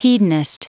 Prononciation du mot hedonist en anglais (fichier audio)